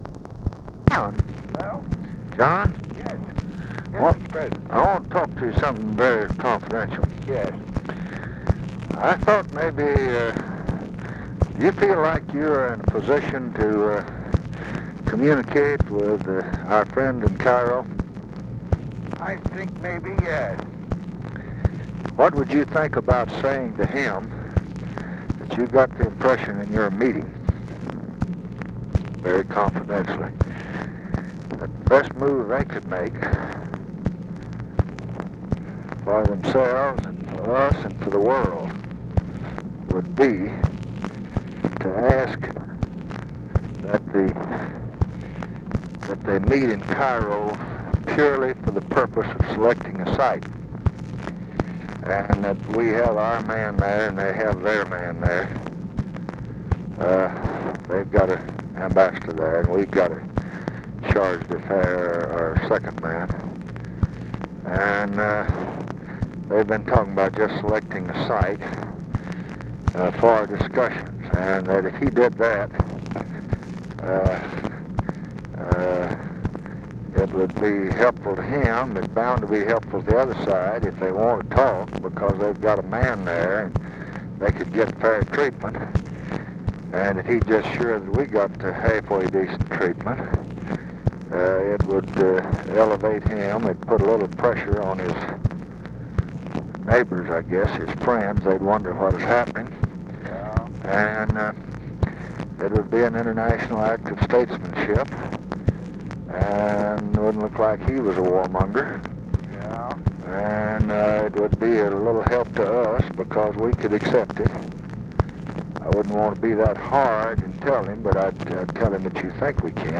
Conversation with JOHN MCCLOY, April 30, 1968
Secret White House Tapes